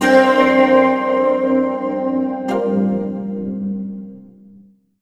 Index of /90_sSampleCDs/USB Soundscan vol.51 - House Side Of 2 Step [AKAI] 1CD/Partition D/02-FX LOOPS